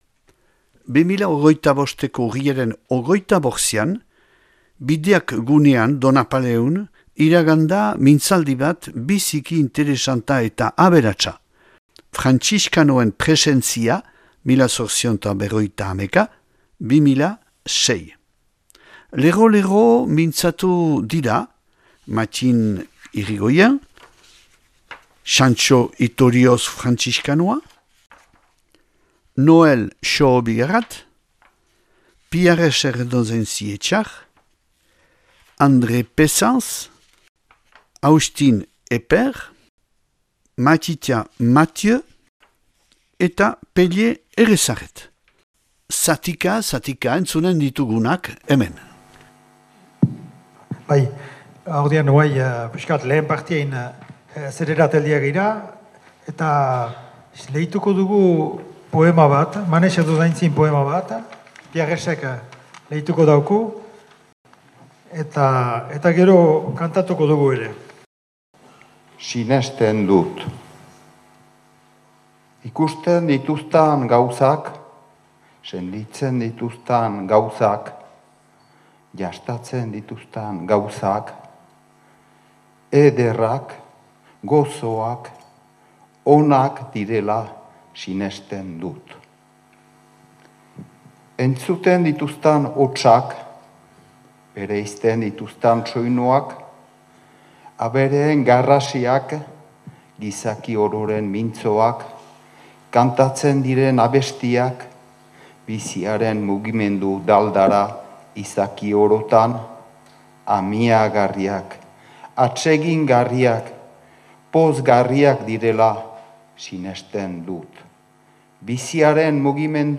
2025ko Urriaren 25ean, Bideak gunean Donapaleun, iragan da mintzaldi bat biziki interesanta eta aberatsa : Frantziskanoen presentzia 1851-2006.